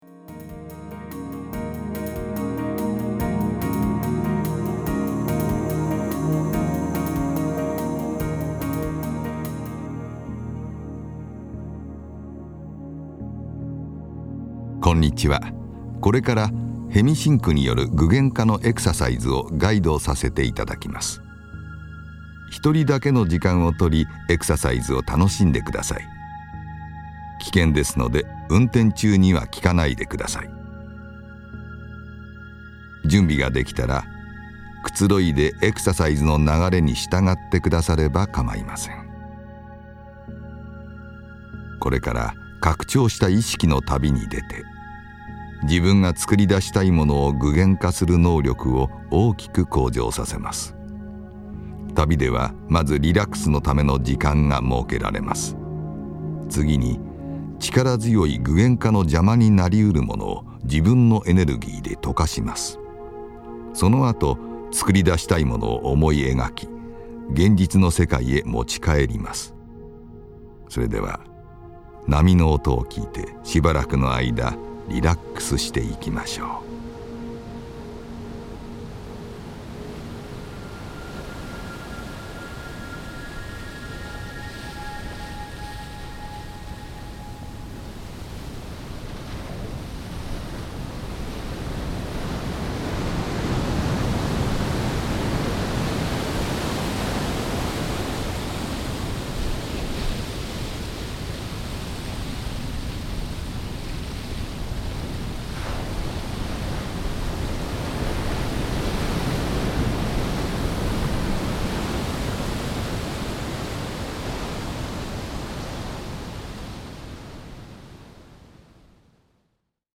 ヘミシンク信号に加えて、ミュージックや音声ガイダンス、あるいはかすかな音響効果などが組み合わされて、その効果はさらに高められます。
（音声ガイダンスあり）
2枚目には、音声ガイドダンス付きのエクササイズが2つ収録されています。